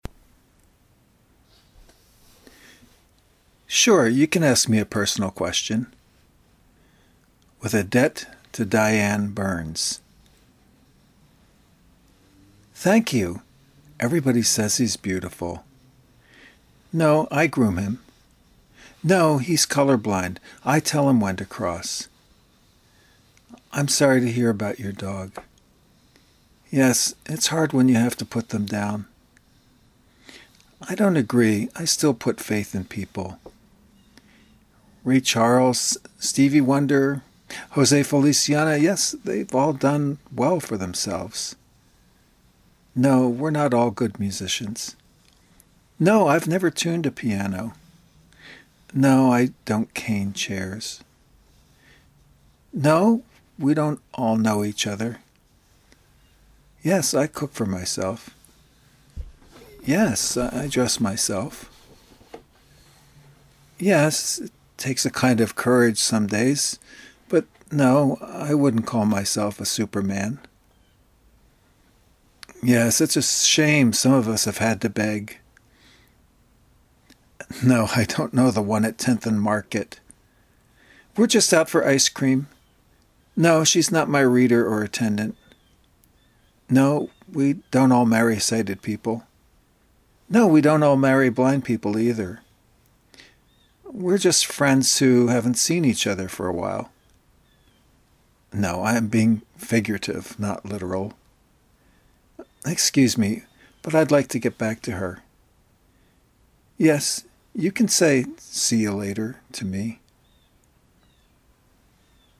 Click to hear this poem out loud.